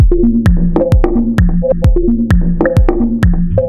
Corals1 130bpm.wav